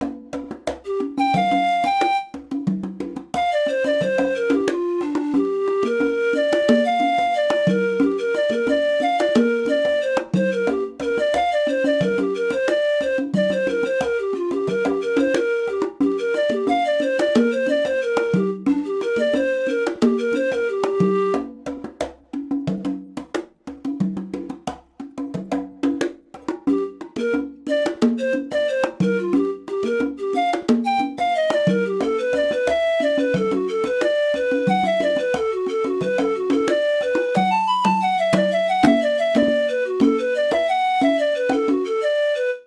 Palavras-chave: Foley